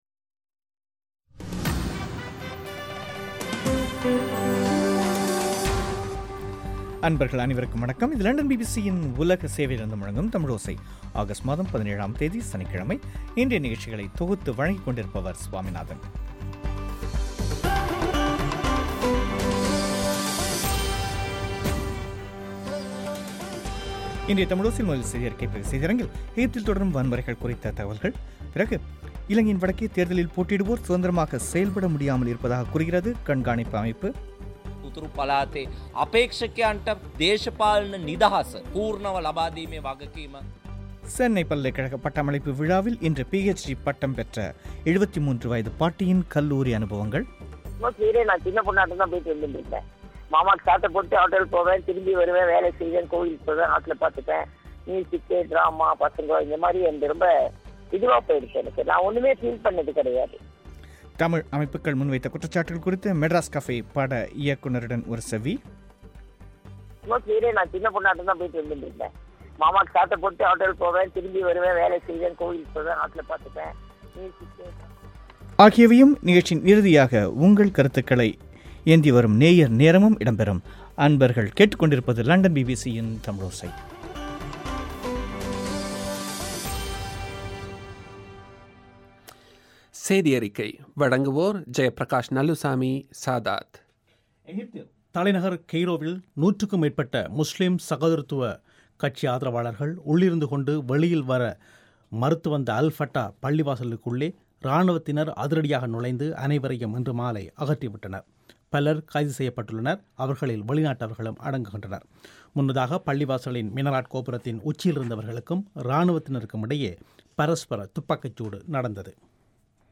இன்றைய தமிழோசையில் எகிப்தில் தொடரும் வன்முறைகள் குறித்த செய்திகளும் இலங்கை வட மாகாணத் தேர்தல் குறித்து தேர்தல் கண்காணிப்பு அமைப்பு வெளியிட்ட கருத்துக்களும் இடம்பெறும்.